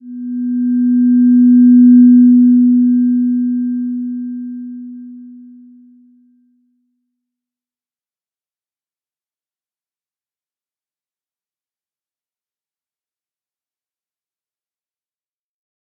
Slow-Distant-Chime-B3-p.wav